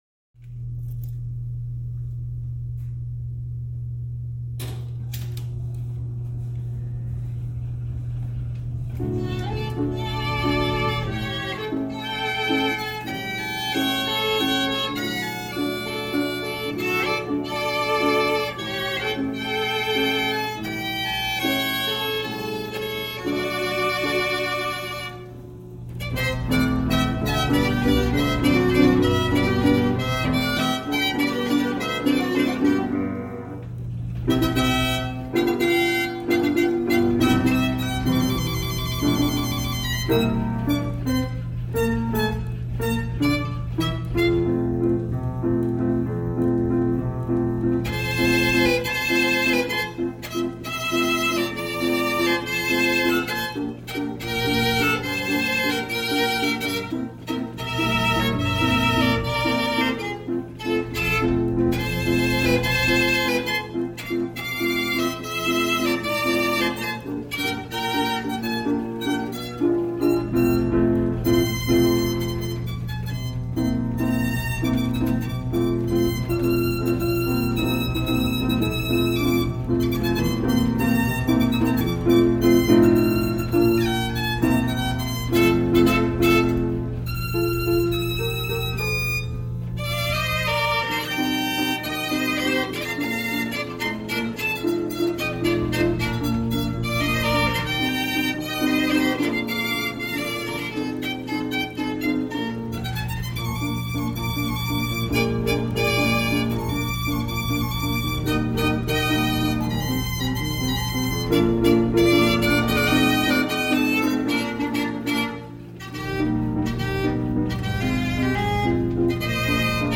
This instrument is thought to be the most human-like of all automatic musical instruments.